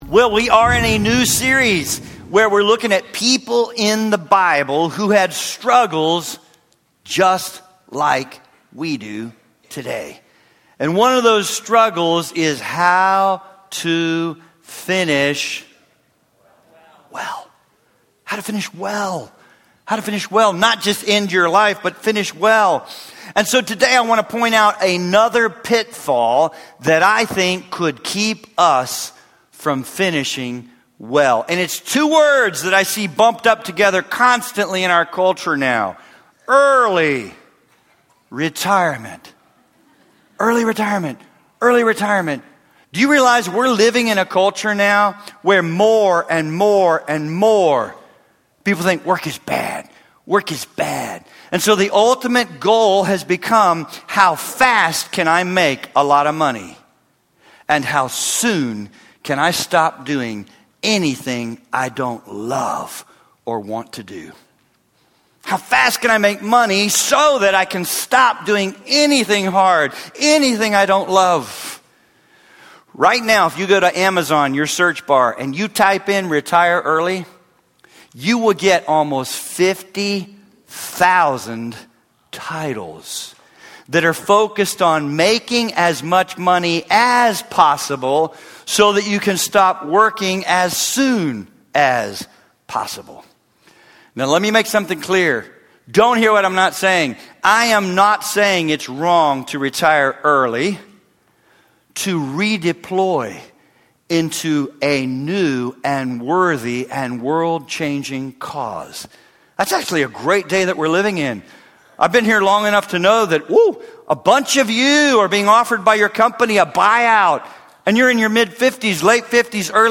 Preaching and teaching from Grace Fellowship Church in Northern Kentucky